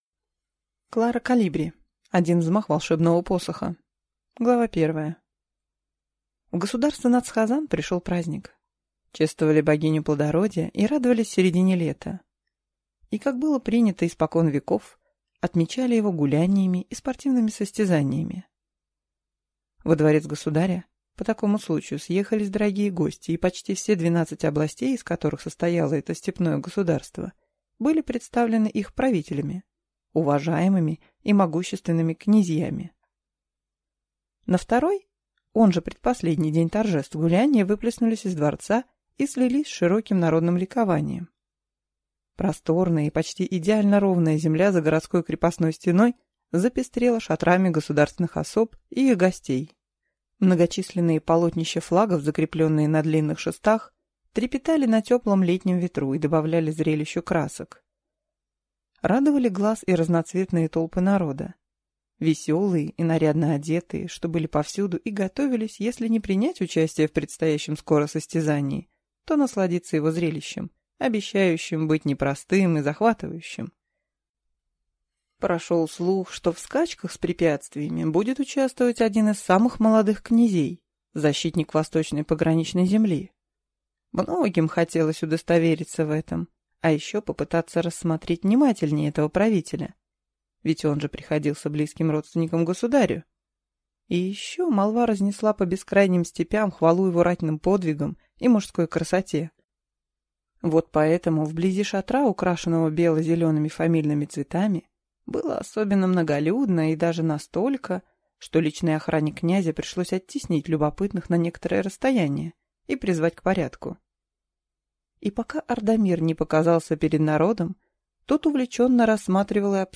Аудиокнига Один взмах волшебного посоха | Библиотека аудиокниг